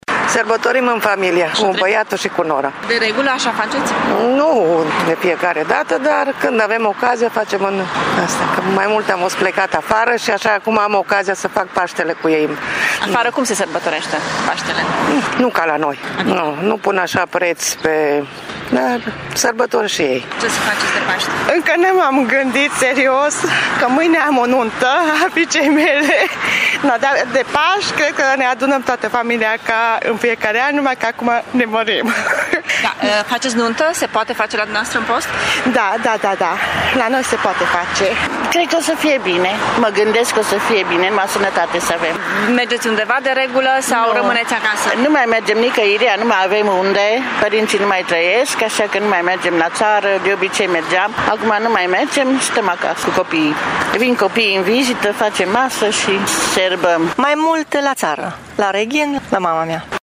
Sărbătorile pascale adună, în fiecare an, familiile laolaltă, spun târgumureșenii. Nicăieri nu e mai bine ca acasă, crede un mureșean care a trăit ani de zile în străinătate: